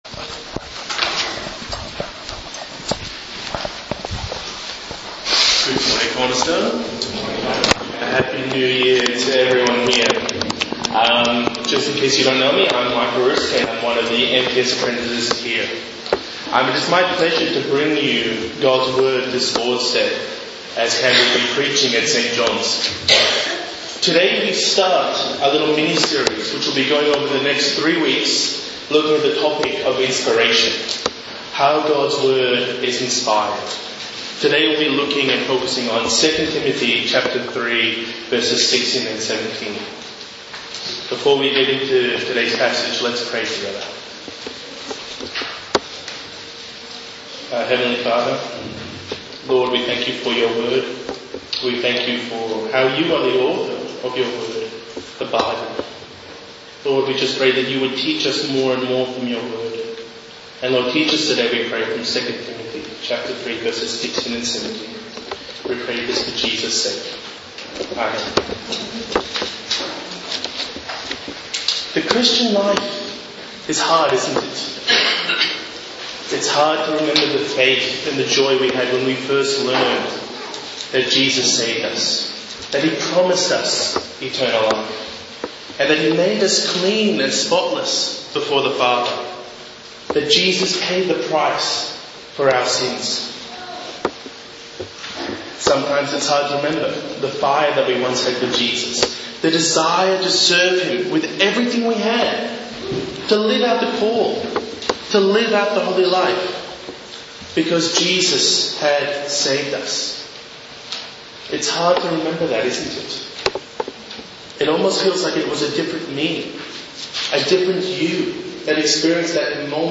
2 Timothy 3:16-17 Sermon